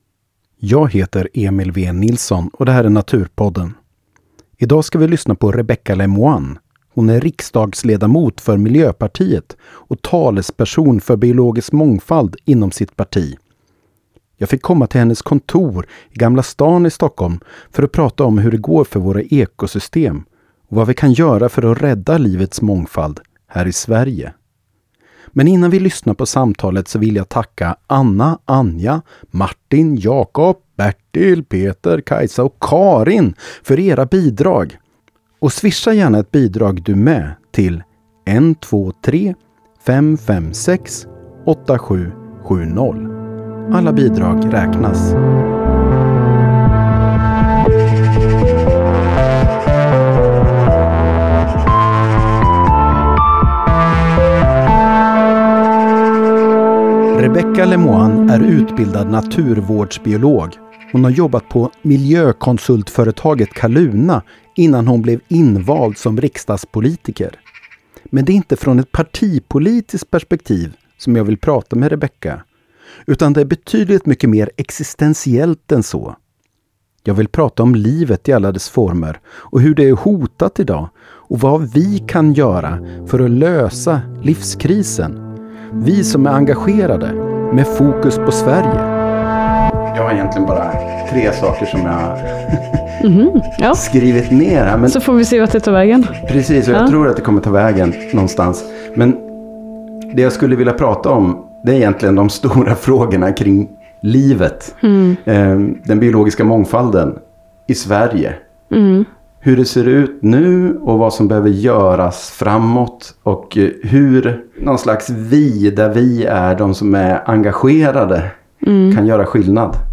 Lyssna på samtalet med Rebecka Le Moine (MP), inspelat på hennes kontor i Gamla stan i Stockholm.